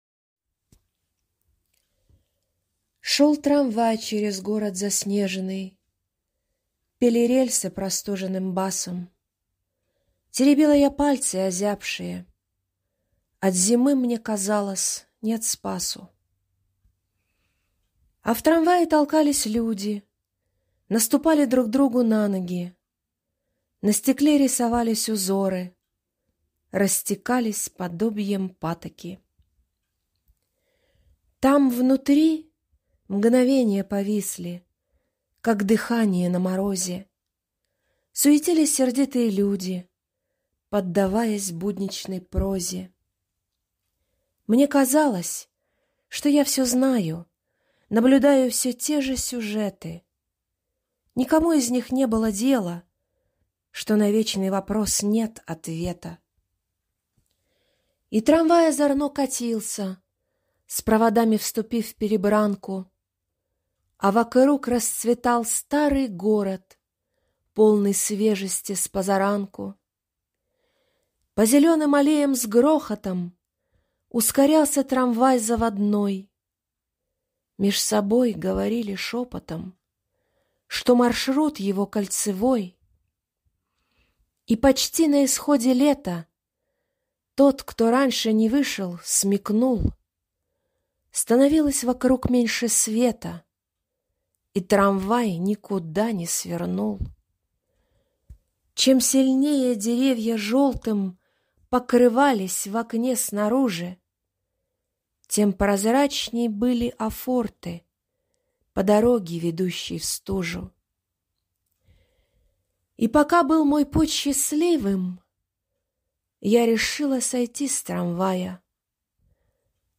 Читает автор